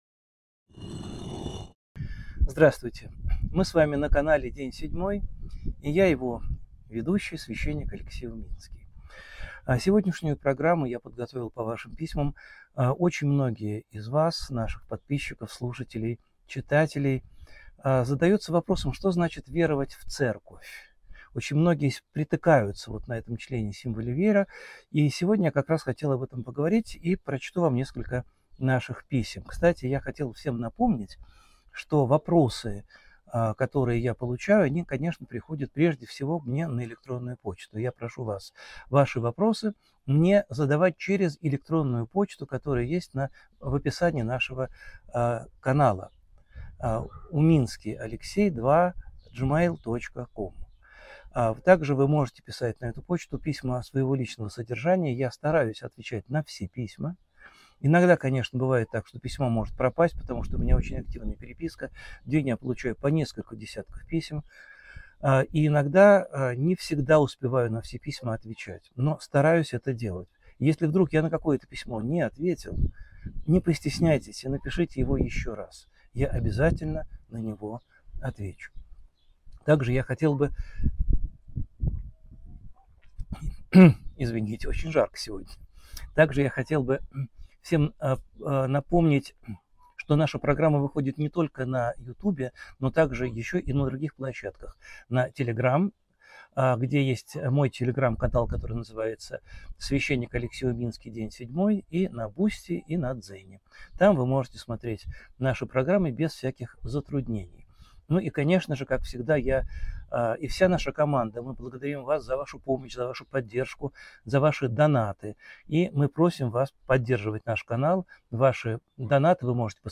Эфир ведёт Алексей Уминский